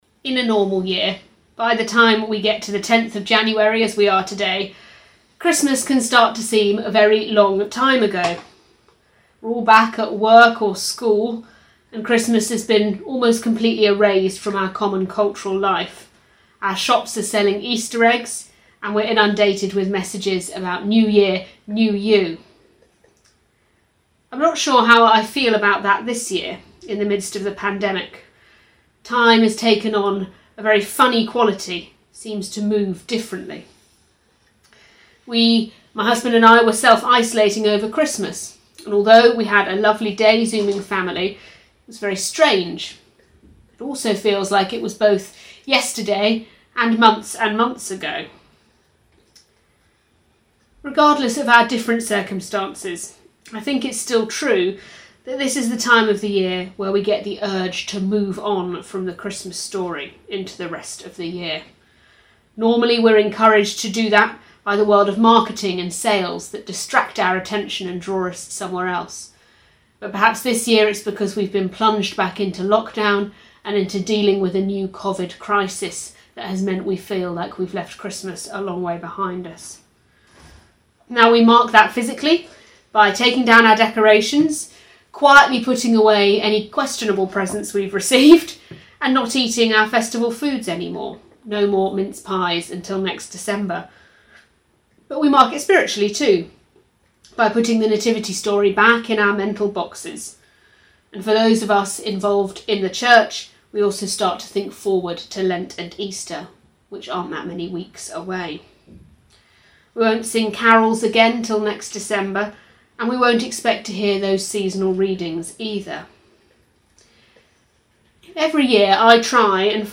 latestsermon-1.mp3